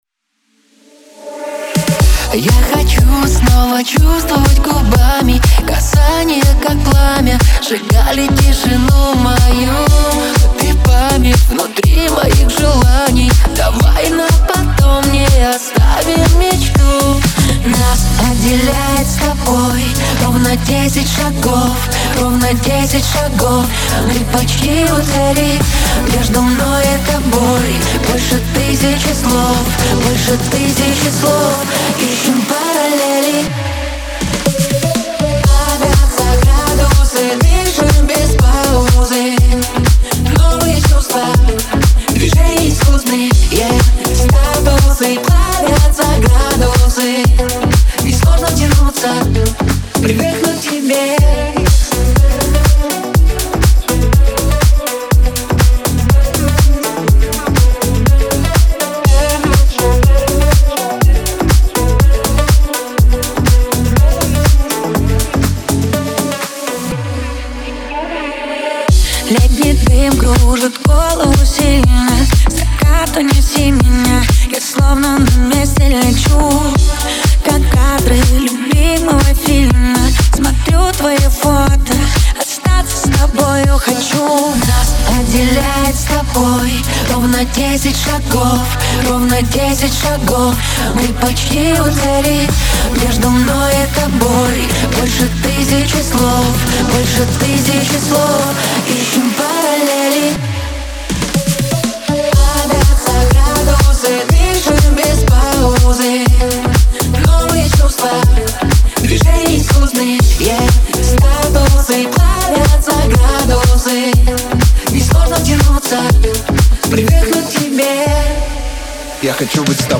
это яркая и энергичная поп-композиция